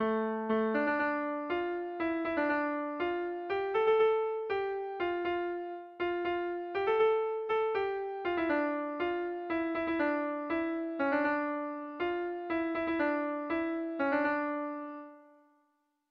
Erromantzea
Silabak orain elkartu eta gero banatu, neurrian baditu gorabeherak, erromantze zahar askoren gisan.
Lauko handia (hg) / Bi puntuko handia (ip)
AB